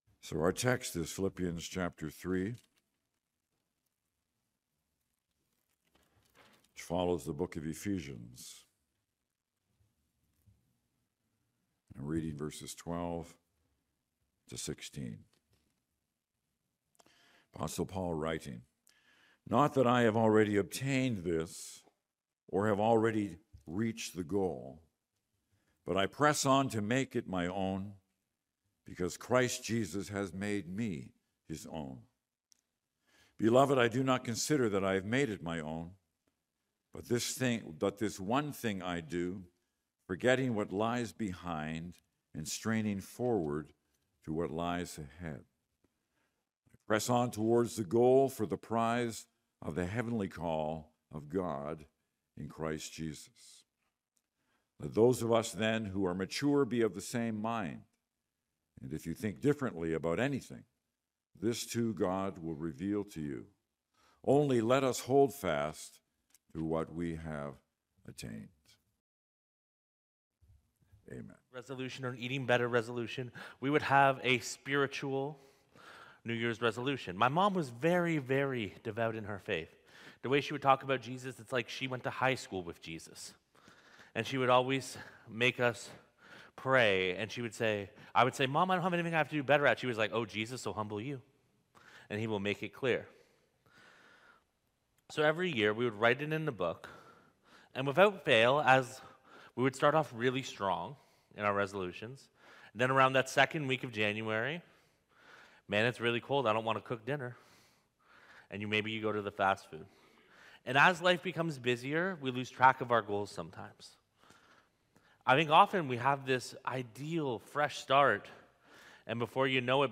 Sermons | Weston Park Baptist Church